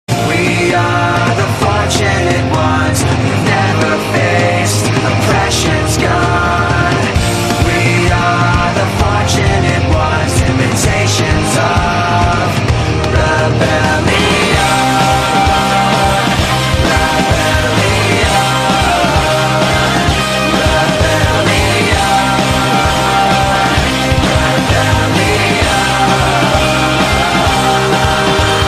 M4R铃声, MP3铃声, 欧美歌曲 144 首发日期：2018-05-15 21:27 星期二